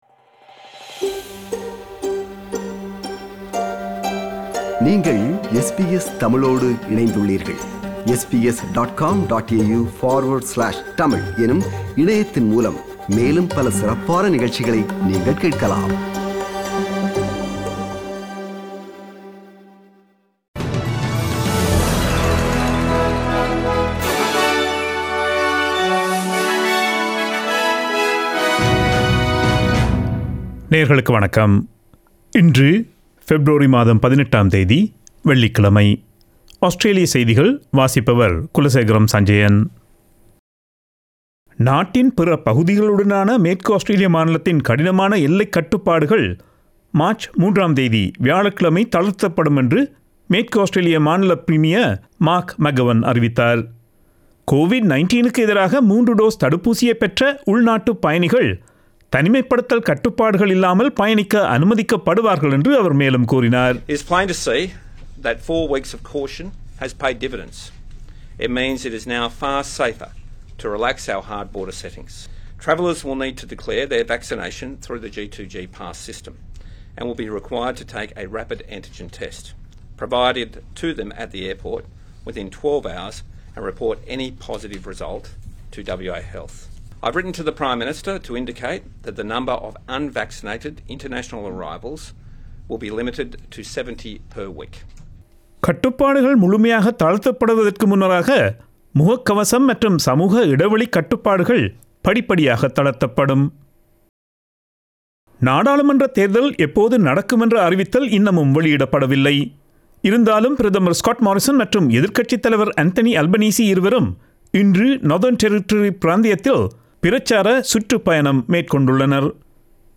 Australian news bulletin for Friday 18 February 2022.